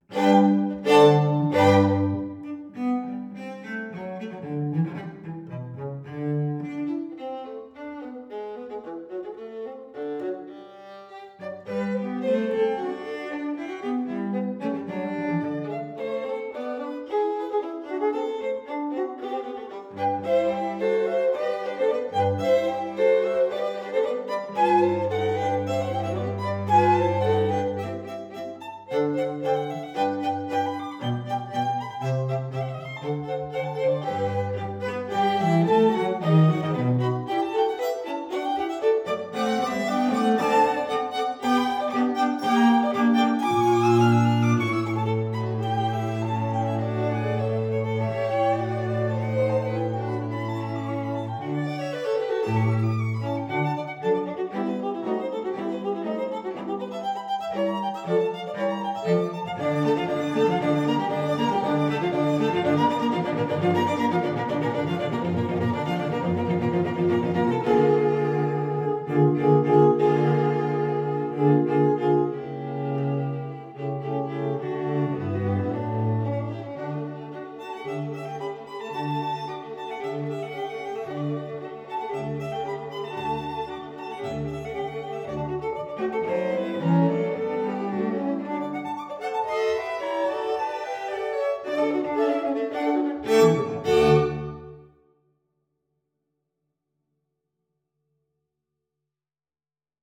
Quartet_HolyT_time_domainP2.wav